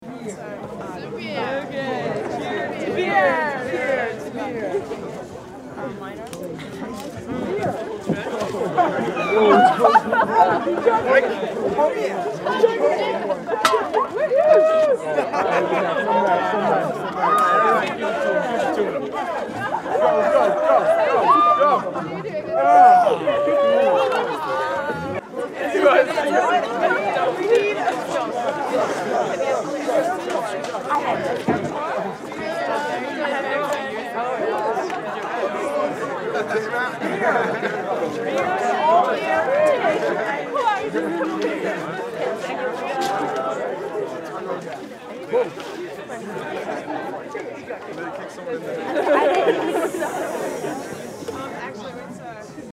6. Развлечения для вечеринки